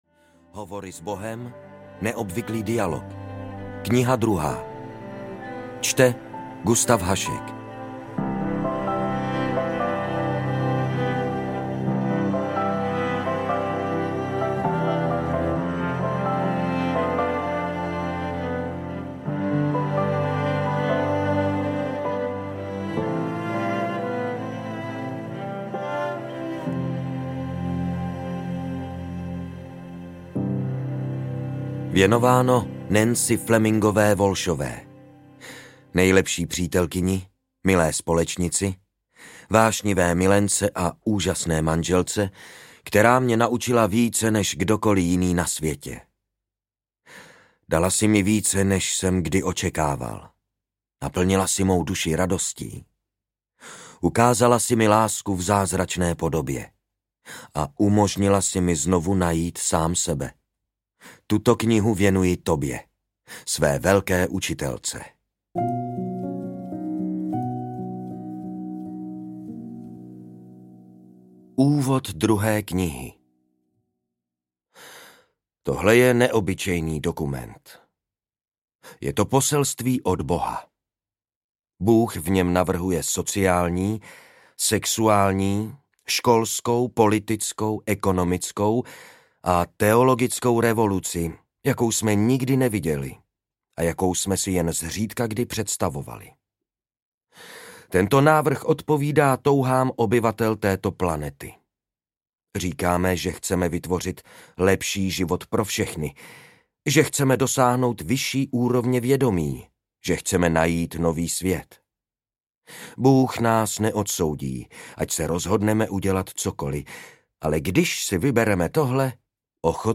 Hovory s Bohem II audiokniha
Ukázka z knihy